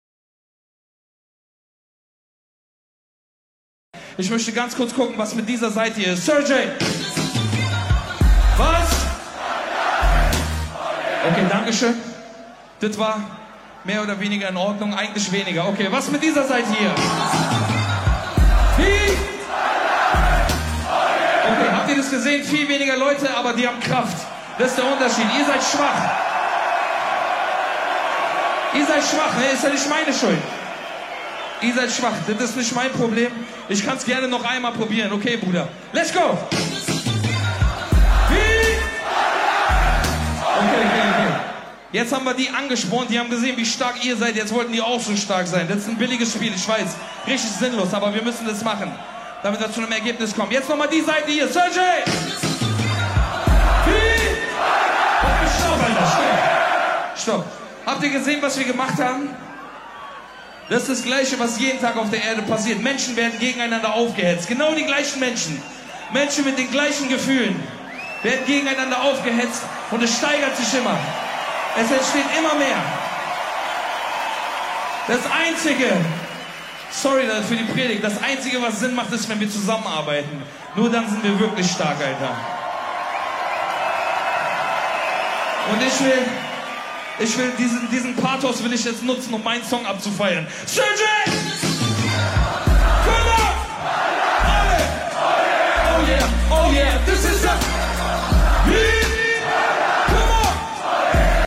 Der Rapper Kool Savas erklärt in paar Sekunden die Teile und Herrsche-Taktik die wir jeden Tag in den Medien sehen und erleben können. Bei einem Live-Konzert beim Splash! Festival stachelt er bewusst die Linke und die Rechte Seite gegeneinander auf und mittendrin...